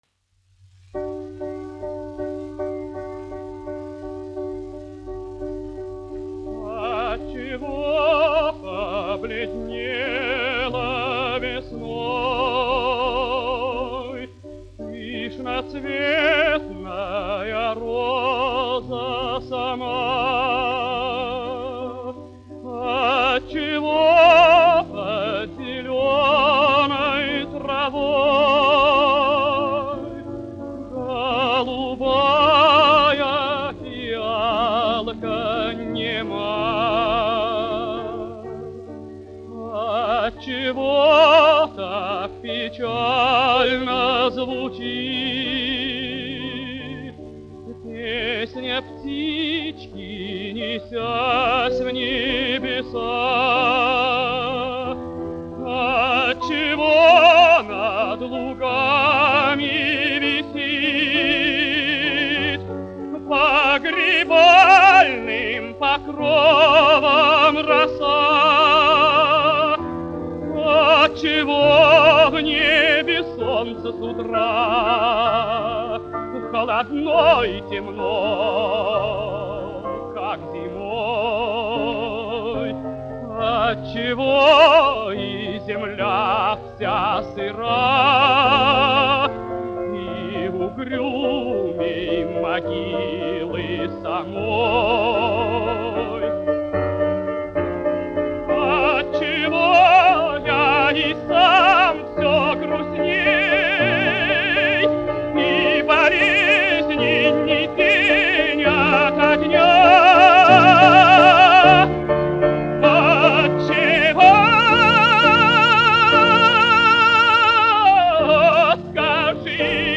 Лемешев, Сергей Яковлевич, 1902-1977, dziedātājs
1 skpl. : analogs, 78 apgr/min, mono ; 25 cm
Dziesmas (augsta balss) ar klavierēm
Latvijas vēsturiskie šellaka skaņuplašu ieraksti (Kolekcija)